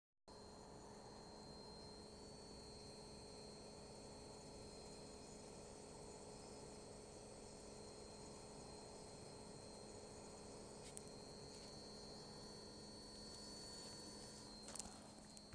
Merci pour vos remarques en espérant que de votre coté tout fonctionne maintenant un bruit "strident" ou type ultrason est émis de l'unité extérieure, puis est transmis via la gaine et arrive via l'unité intérieure du gainable dans la buanderie et c'est insupportable.
doublon 740438 unite exterieure.mp3
Votre sifflement est vers les 4 kHz, il est dû au hachage d'une tension, soit au niveau de l'IPM (qui envoie non pas des sinusoïdes triphasées sur le compresseur, mais des signaux très complexes à base d'allers/retours entre le 0V et la haute tension, 340V continus), soit au niveau du PFC qui hache la tension secteur pour qu'elle soit plus stable sur les gros condensateurs 400V... A chaque fois des bobinages vibrent, ceux du compresseur ou l'inductance PFC.